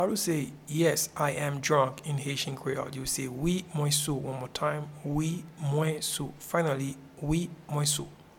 Pronunciation and Transcript:
Yes-I-am-drunk-in-Haitian-Creole-Wi-mwen-sou.mp3